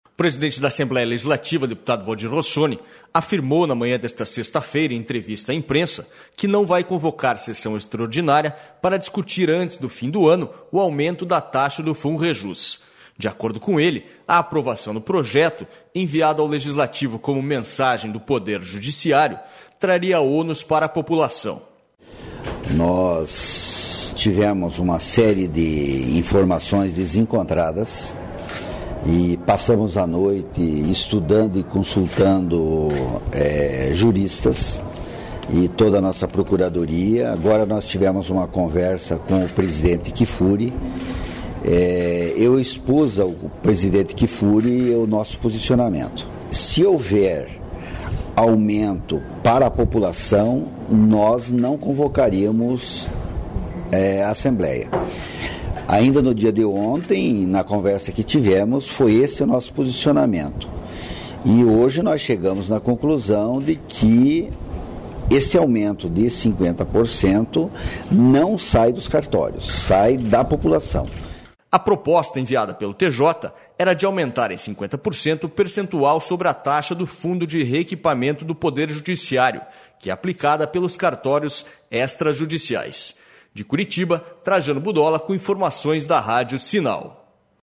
O presidente da Assembleia Legislativa, deputado Valdir Rossoni, afirmou na manhã desta sexta-feira em entrevista à imprensa, que não vai convocar sessão extraordinária para discutir antes do fim do ano o aumento da taxa do Funrejus.//